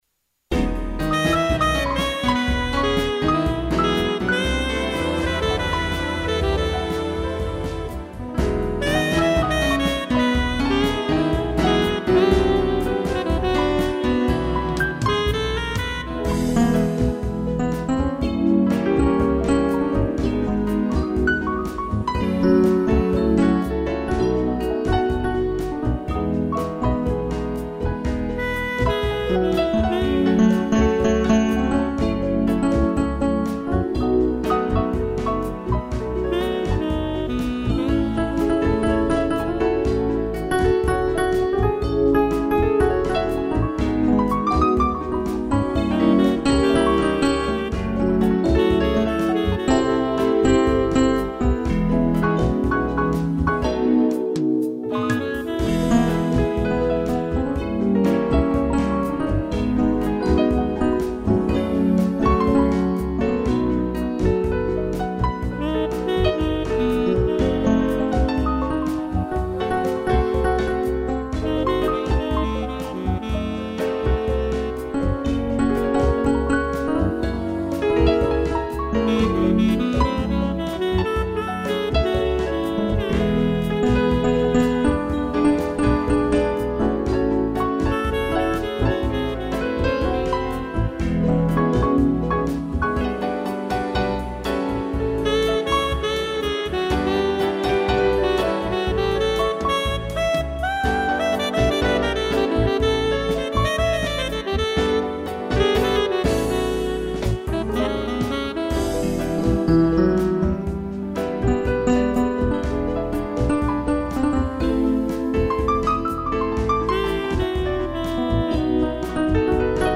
piano e sax
instrumental